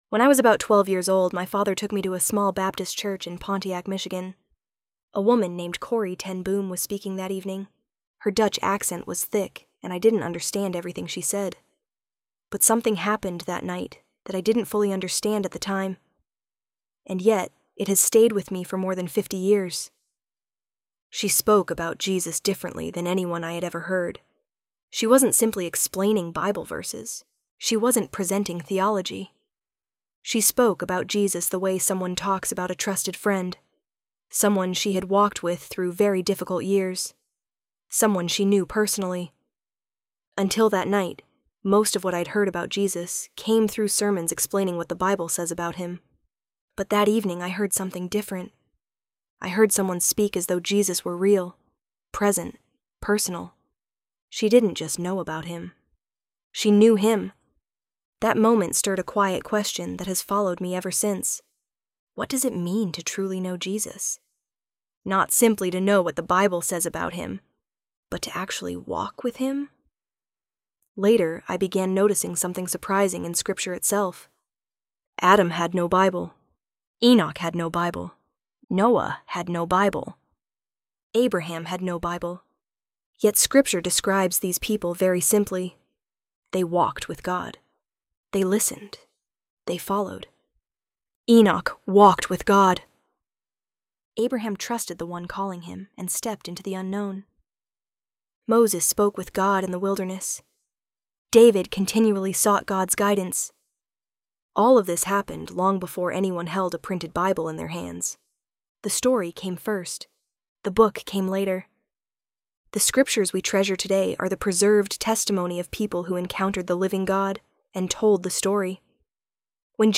ElevenLabs_Before_There_Was_a_Bible.mp3